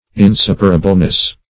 Definition of inseparableness.
Search Result for " inseparableness" : The Collaborative International Dictionary of English v.0.48: Inseparableness \In*sep"a*ra*ble*ness\, n. The quality or state of being inseparable; inseparability.